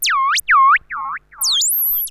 Theremin_FX_04.wav